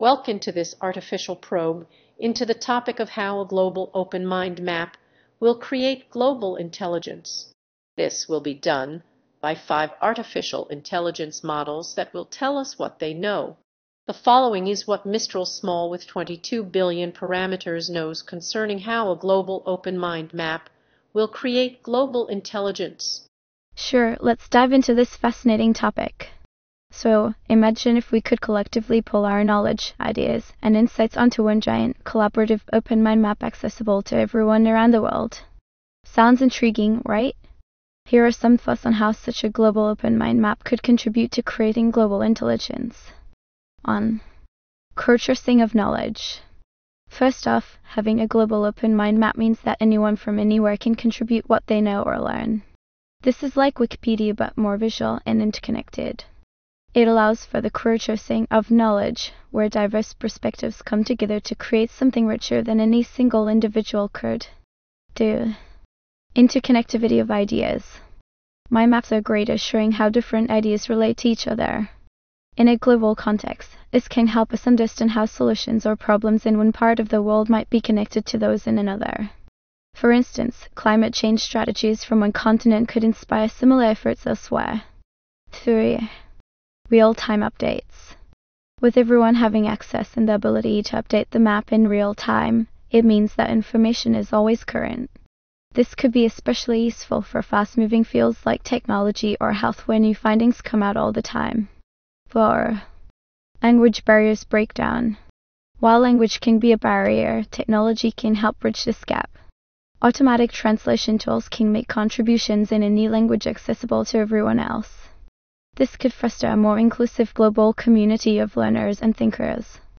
What benefits arise by the creation of a Global Open Mind Map? Five AI models have been invited to discuss the benefits of a Global Open Mind Map might provide humankind.